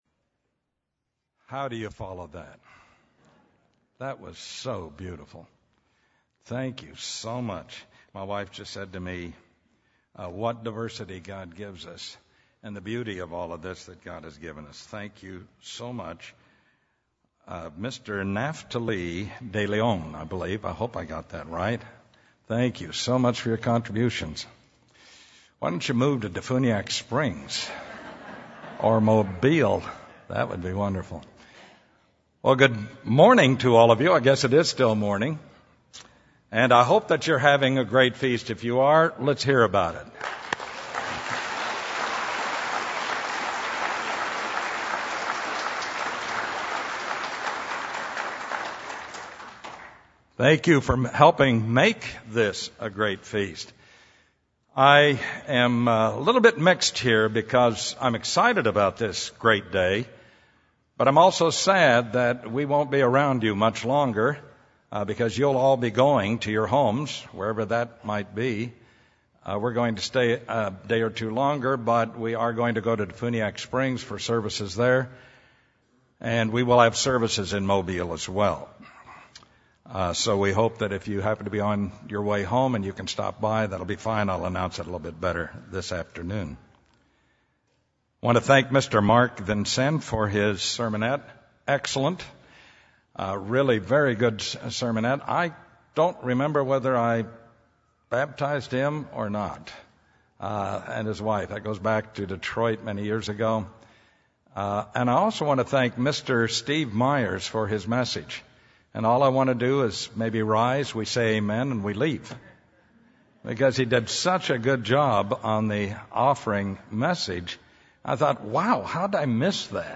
This sermon was given at the Panama City Beach, Florida 2014 Feast site.